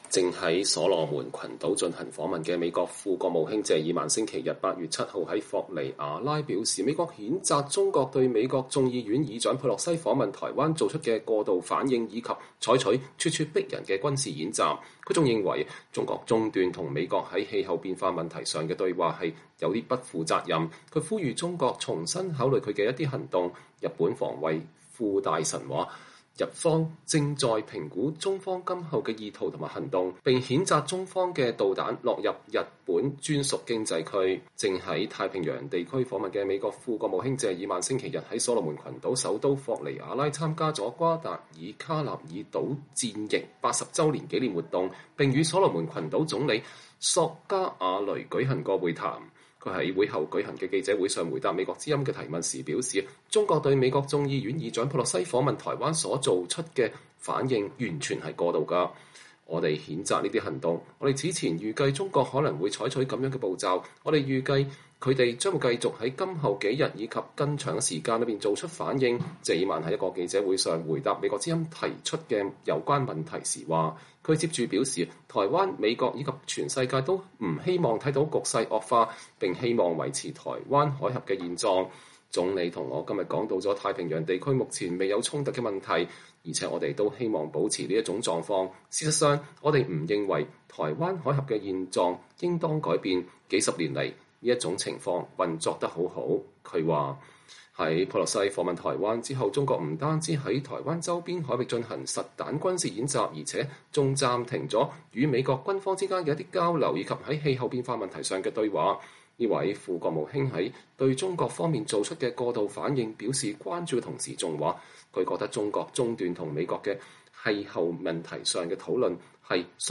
“我們譴責這些行動。我們此前預計中國可能會採取這樣的步驟。我們預期他們將繼續在今後幾天以及在更長的時間裡做出反應，”謝爾曼在一個記者會上回答美國之音記者提出的有關問題時說。